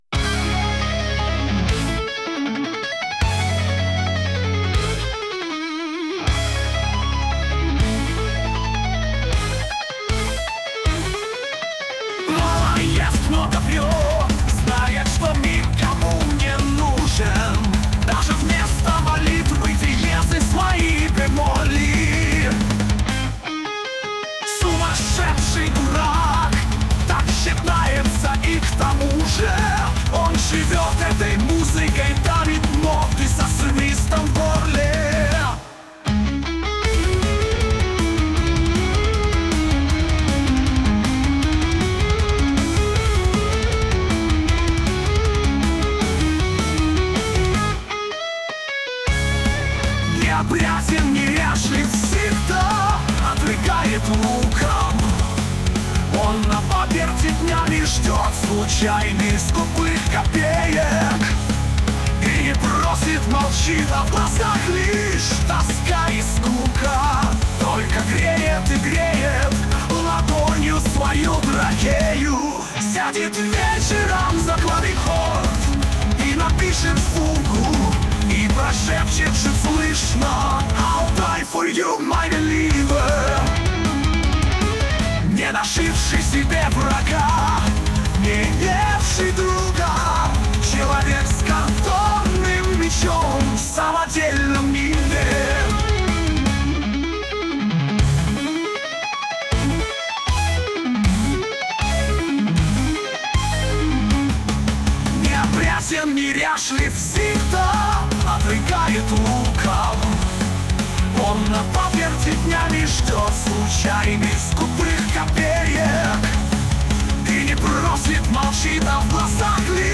Песни с оркестровками [108]